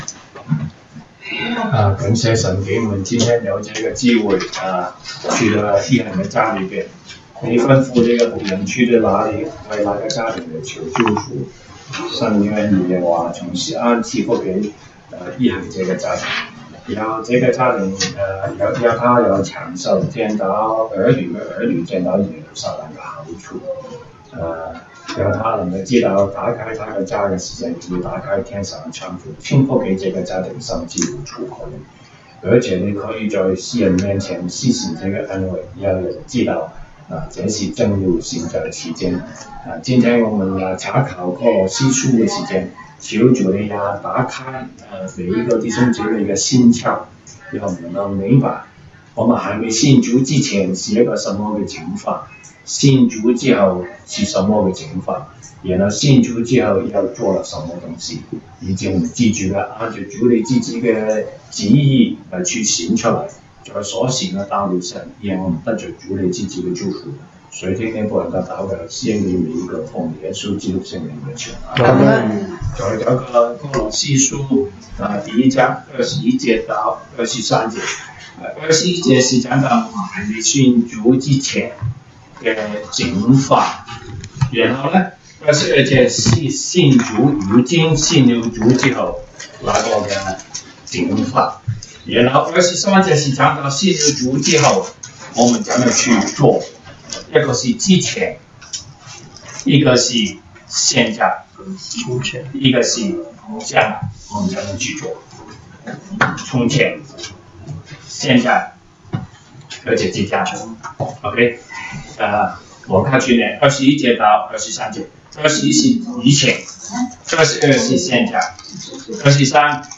Monday Bible Study